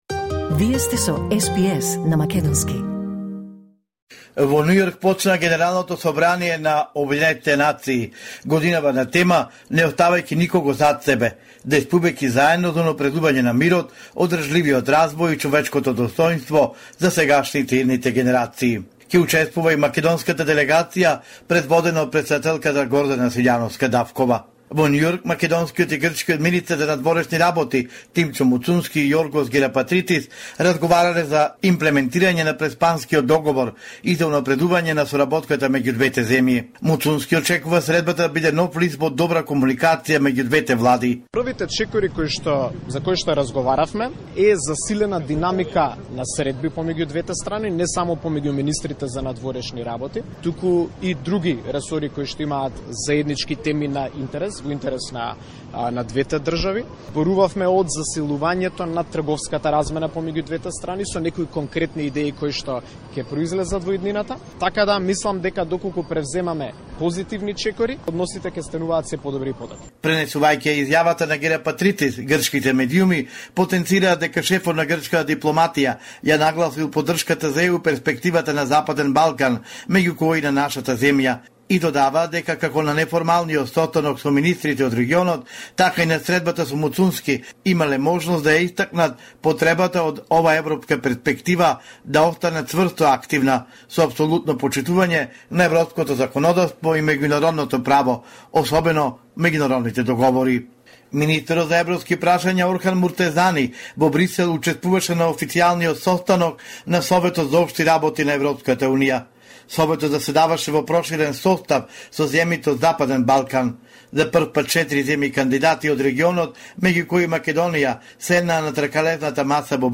Homeland Report in Macedonian 25 September 2024